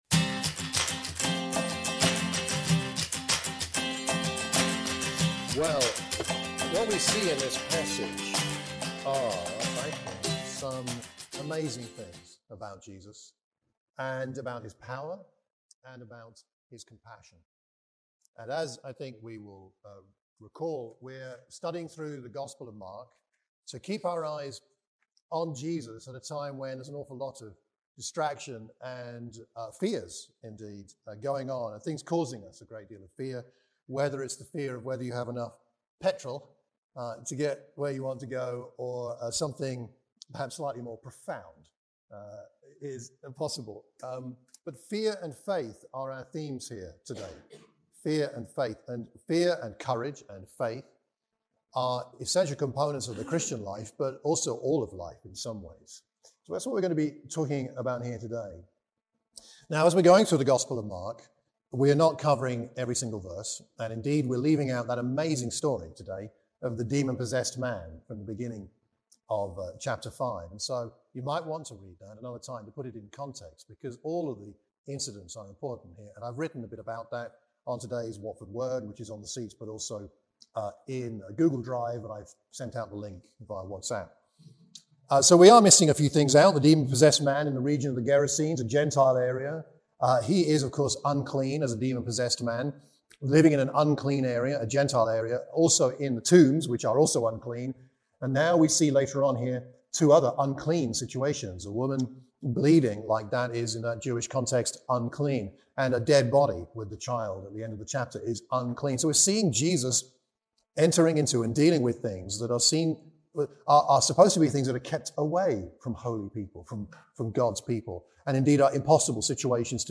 In this sermon for the Watford church of Christ we look at the Markan sandwich of the healing of the daughter of Jairus, and the woman who suffered from bleeding for 12 years.